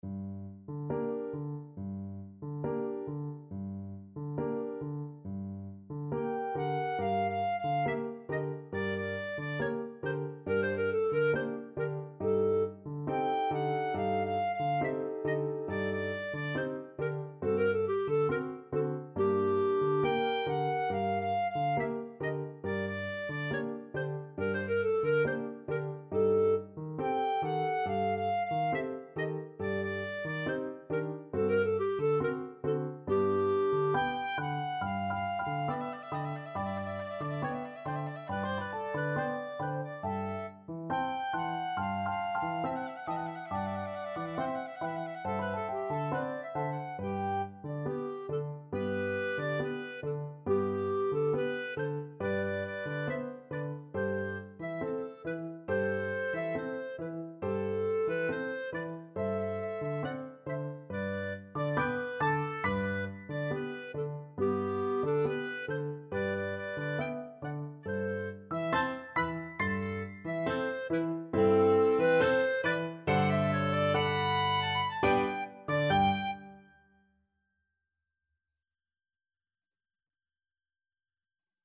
Clarinet 1Clarinet 2Piano
2/4 (View more 2/4 Music)
Classical (View more Classical Clarinet Duet Music)